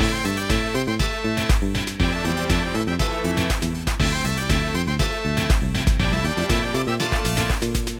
Source AUX cable connected from my 3DS to my PC.